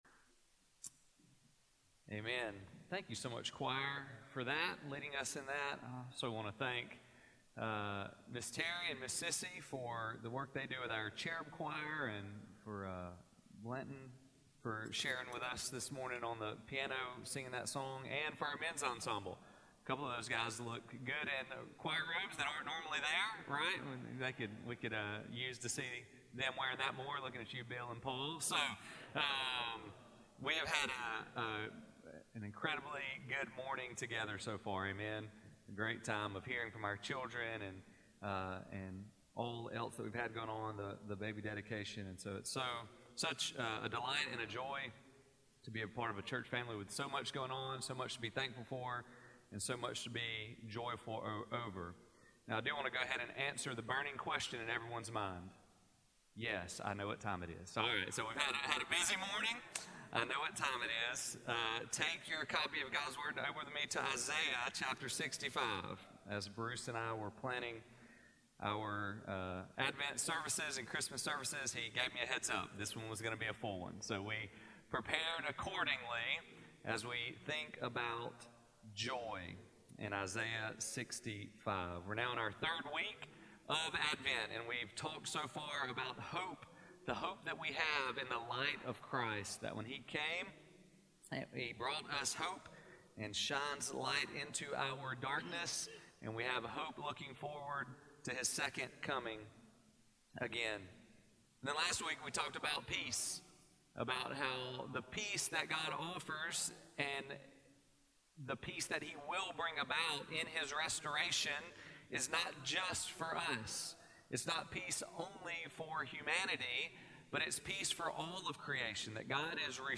Christmas 2025, The Third Sunday of Advent: Joy